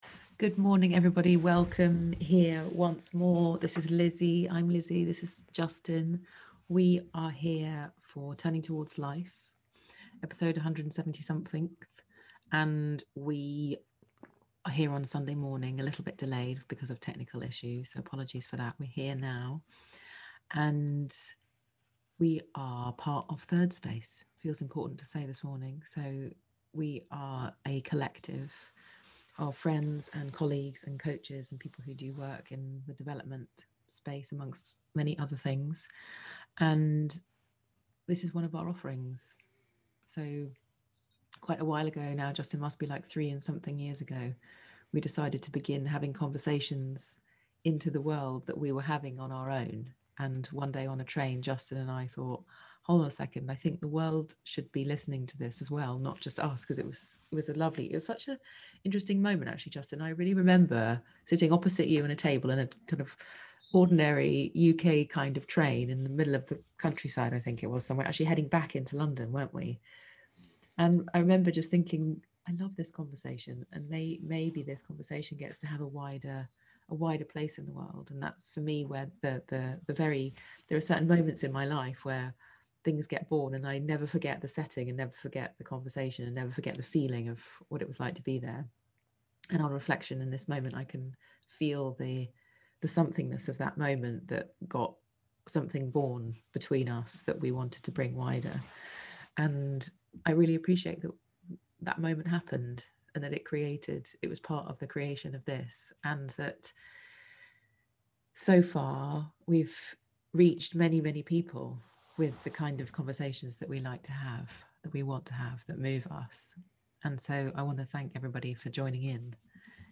A conversation about the essential goodness of people, how it gets misdirected by our misunderstanding ourselves, and the gifts of people who see our goodness, support our stopping our usual defensive ways, and who gladly welcome our remaking… and the possibility of being that...